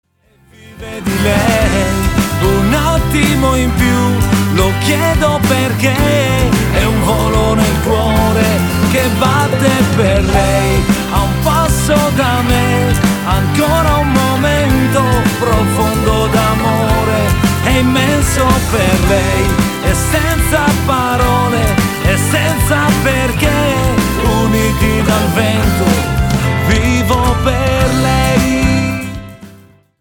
POP  (3.38)